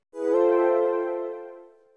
Menu Turn Center.wav